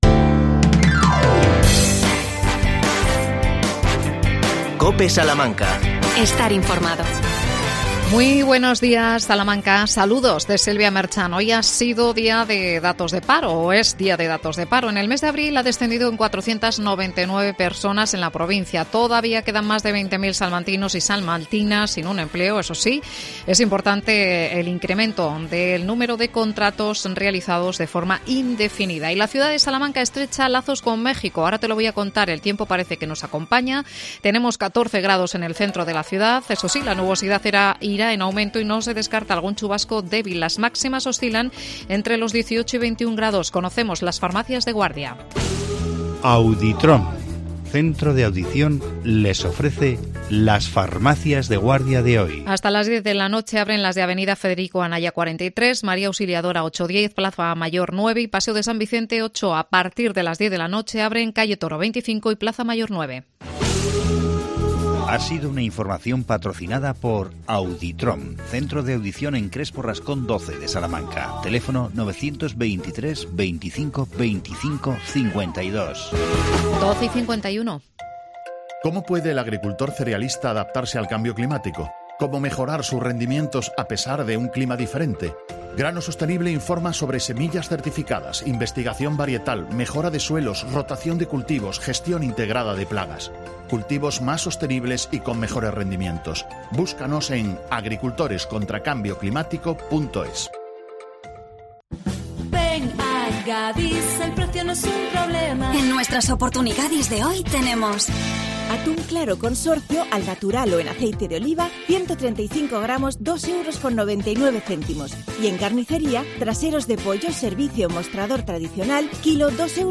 AUDIO: Hermanamiento entre la Universidad de la Universidad Autónoma de Nuevo León y Salamanca. Entrevista